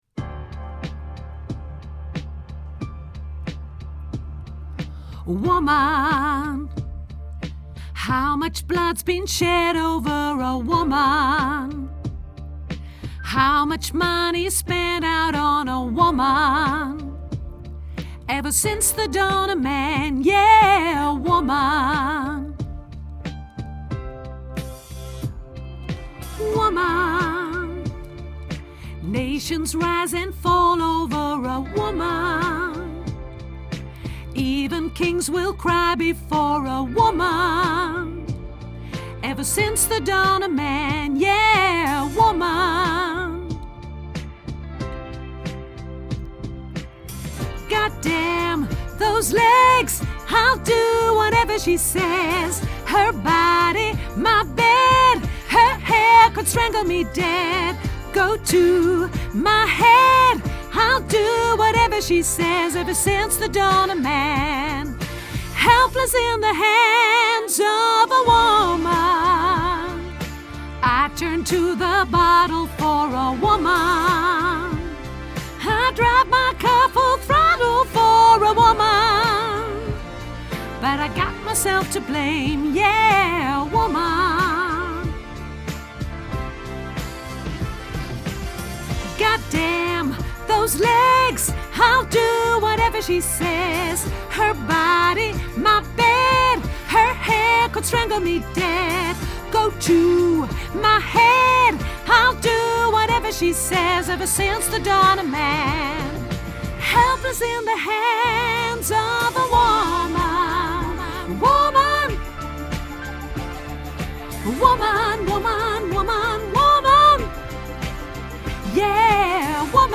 mezzo sopraan
Woman Mezzo Sopraan Grote Koor Mp 3